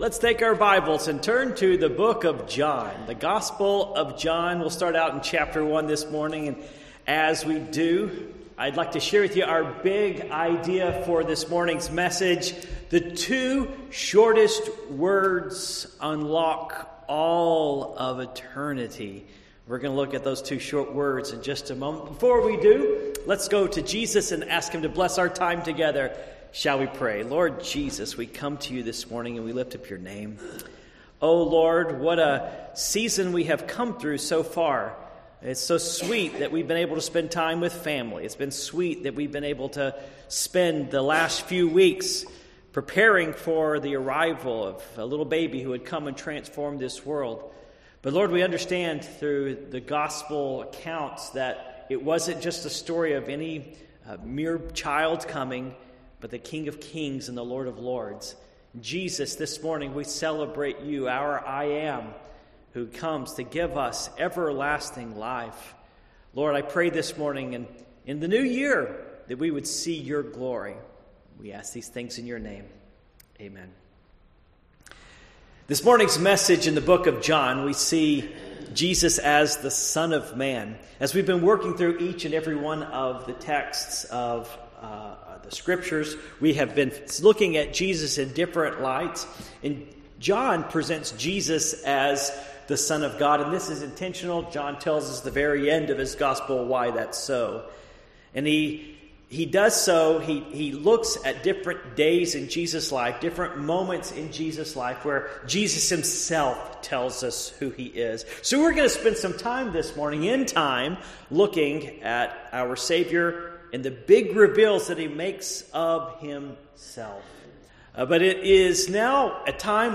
Passage: John 1:1-5 Service Type: Morning Worship